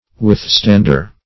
Withstander \With*stand"er\, n.